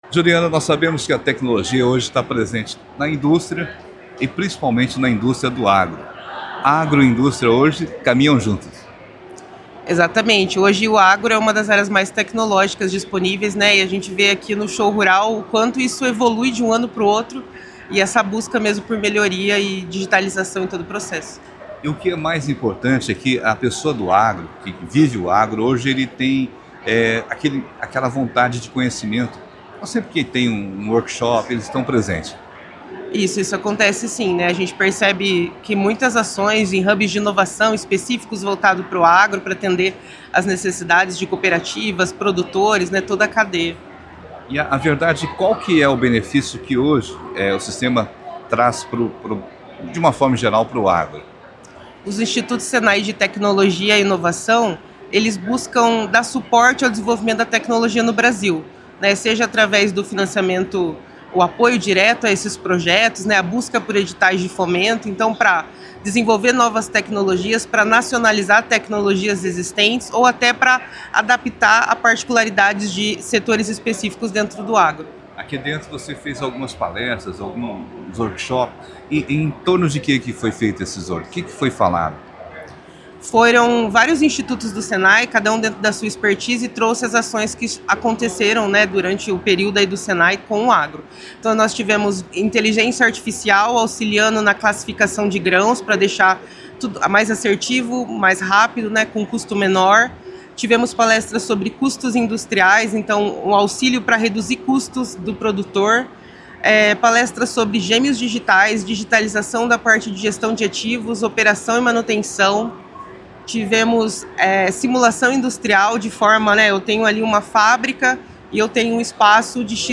falou em entrevista à CBN durante a 38ª edição do Show Rural Coopavel sobre a programação do Sistema Fiep no evento. Ela explicou que as palestras apresentaram soluções da área de tecnologia e inovação do Senai, voltadas para o crescimento e modernização do agronegócio, oferecendo ferramentas e conhecimentos aplicáveis às propriedades rurais e cooperativas presentes na feira.